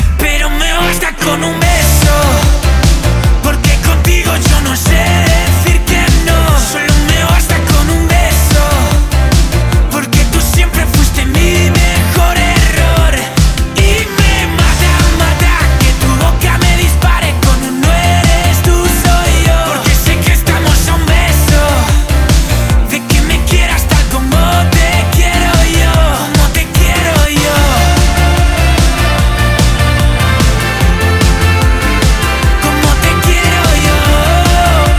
Жанр: Поп
# Pop